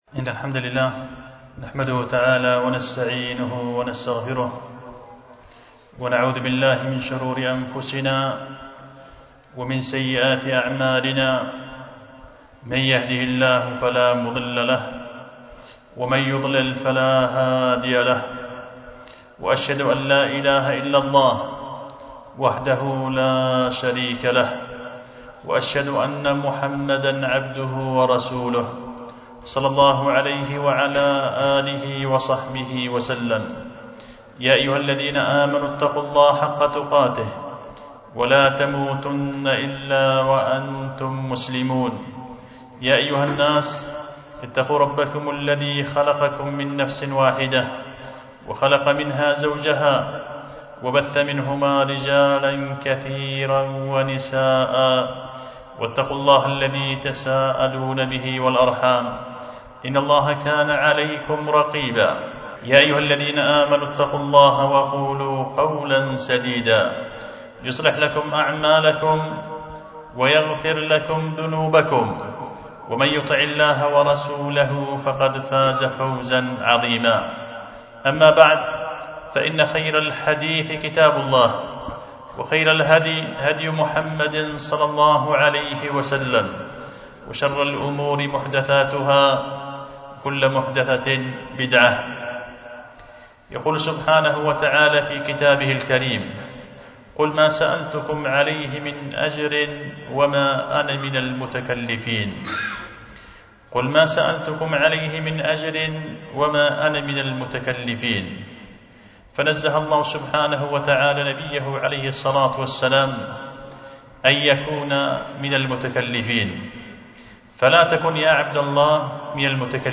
خطبة جمعة بعنوان القول المزبور في ذم التكلف في الأمور